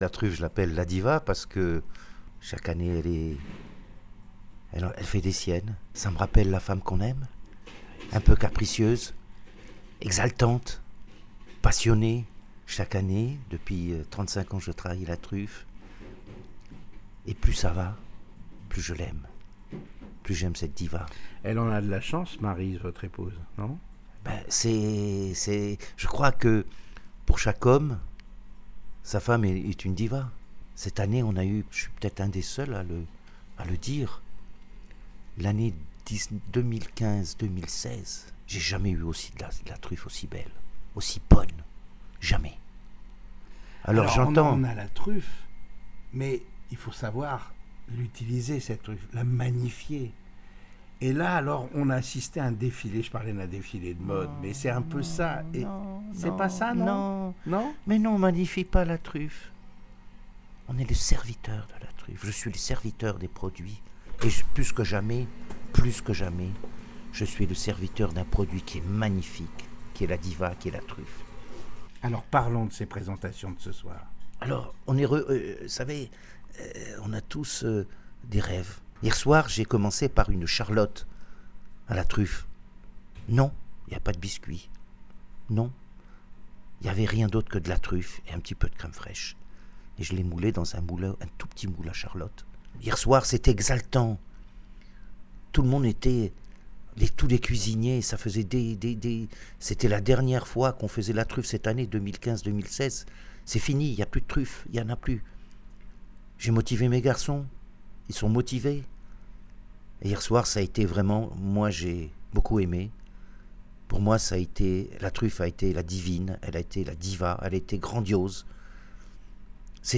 Michel Trama explique pourquoi il compare la truffe à une diva…